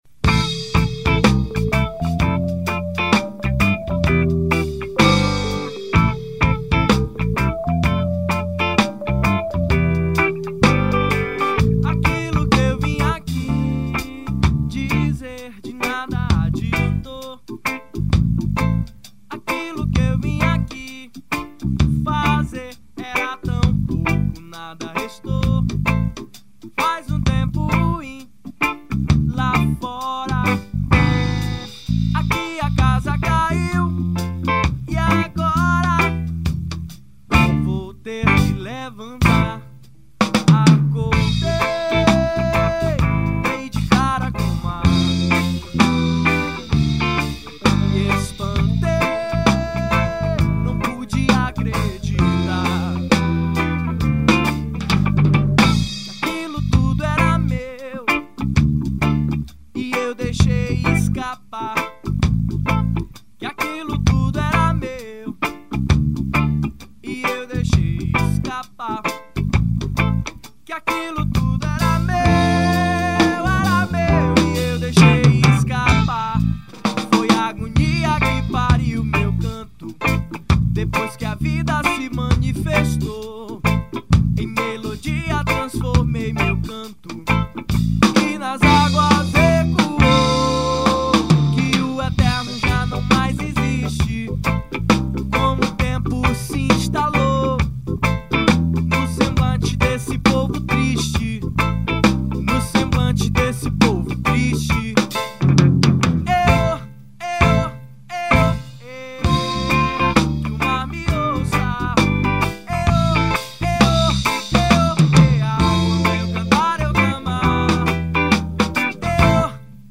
1991   03:02:00   Faixa:     Reggae